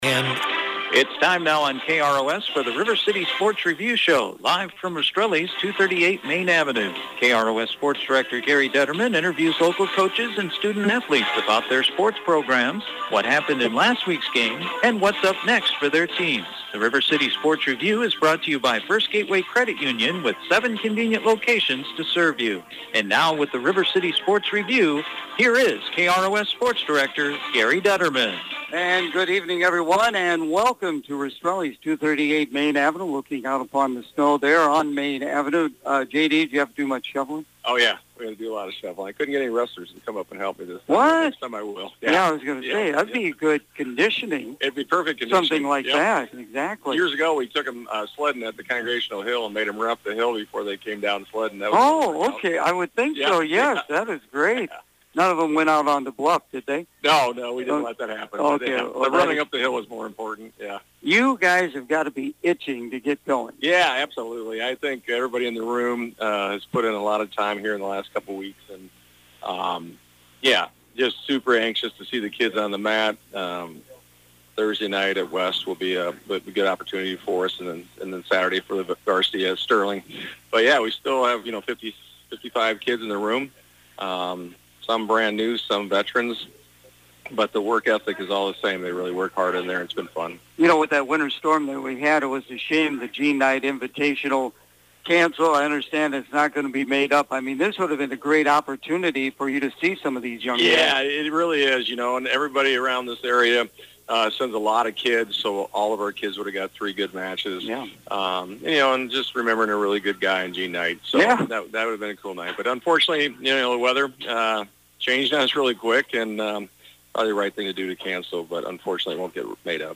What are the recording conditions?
The River City Sports Review Show on Wednesday night from Rastrelli’s Restaurant